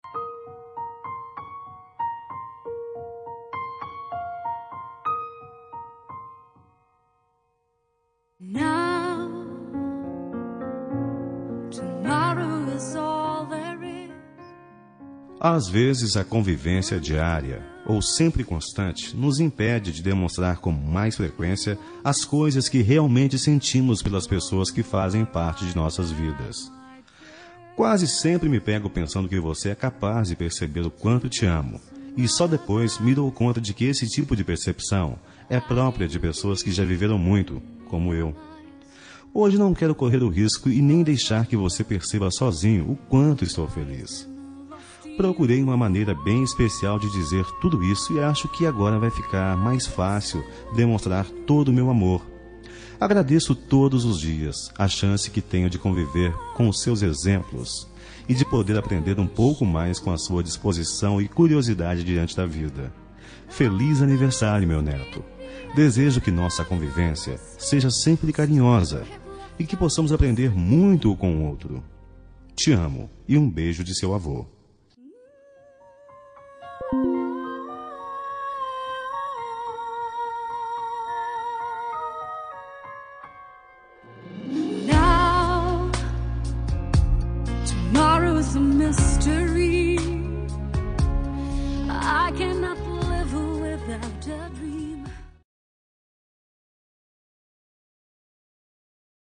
Aniversário de Neto – Voz Masculina – Cód: 131059